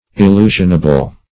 Meaning of illusionable. illusionable synonyms, pronunciation, spelling and more from Free Dictionary.
Illusionable \Il*lu"sion*a*ble\, a.